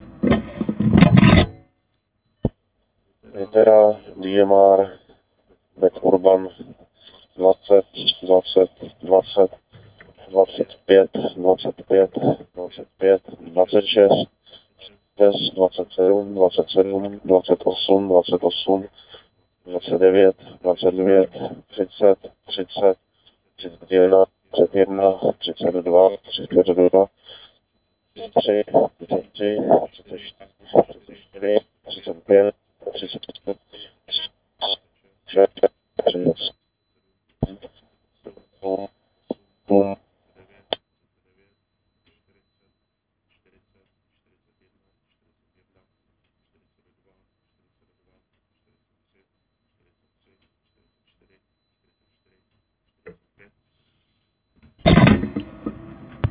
PD785_5km_BadUrban_DMR.wav